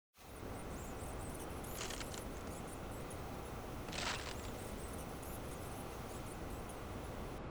楼道场景3.wav